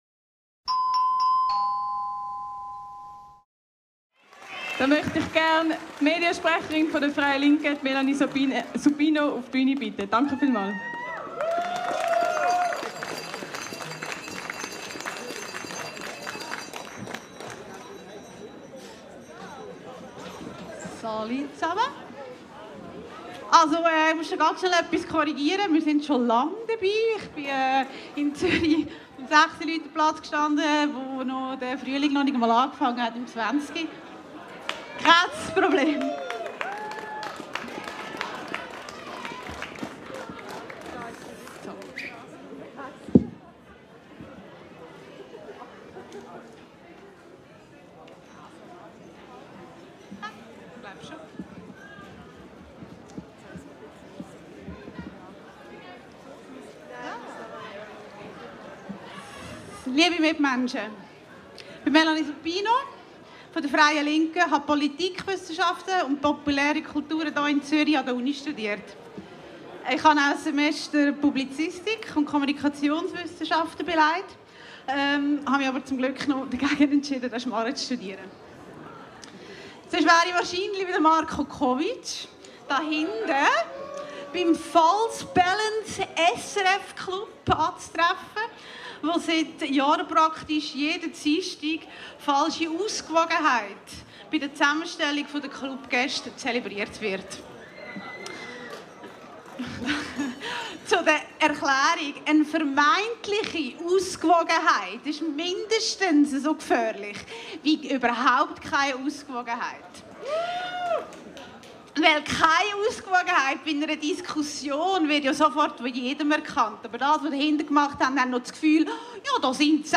Brandrede vor dem SRF Gebäude